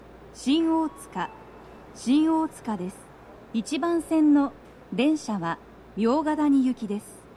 スピーカー種類 BOSE天井型
足元注意喚起放送の付帯は無く、フルの難易度は普通です
到着放送3